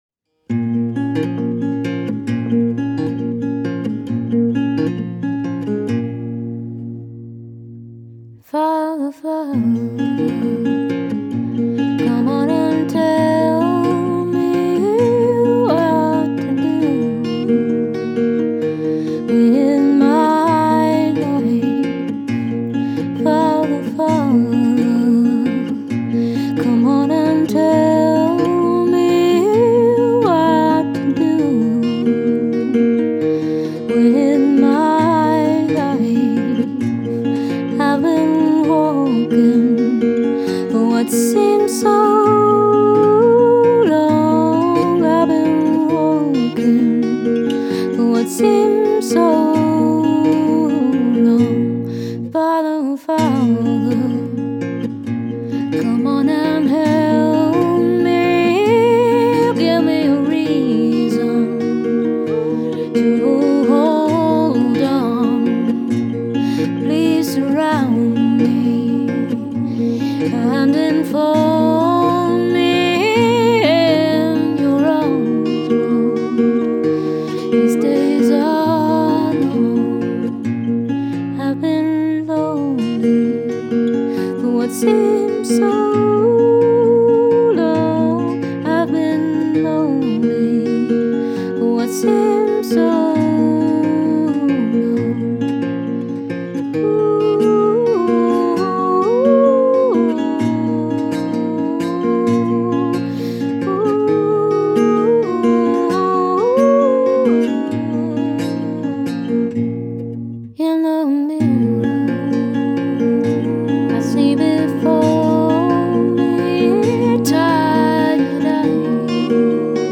and a chance to record in a studio with someone experienced.
layered vocals
guitar playing. Father combines these qualities with a raw, honest and powerful lead vocal and lyrics.
and it was a privilege to work on such a personal, emotional and beautiful song.